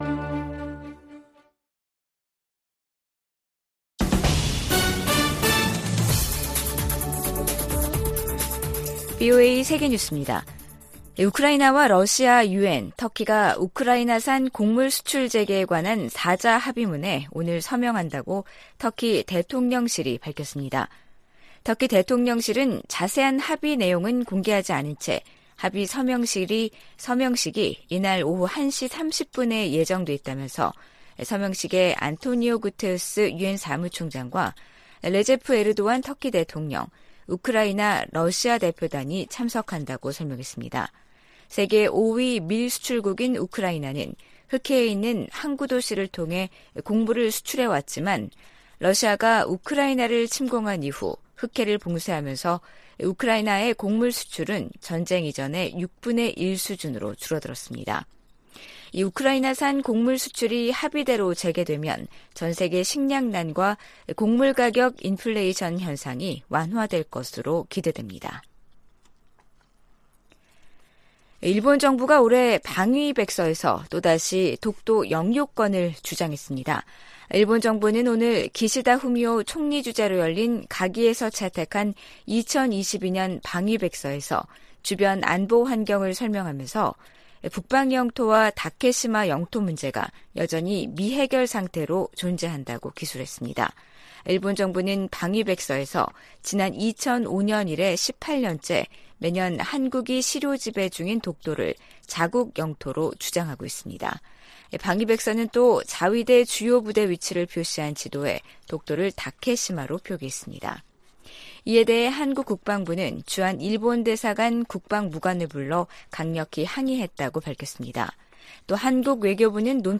VOA 한국어 간판 뉴스 프로그램 '뉴스 투데이', 2022년 7월 22일 2부 방송입니다. 한국 국방부는 대규모 미-한 연합연습과 야외기동훈련을 올해부터 부활시킬 방침이라고 밝혔습니다. 미국은 한국과 일본의 핵무장을 절대 지지하지 않을 것이라고 고위 관리가 전망했습니다.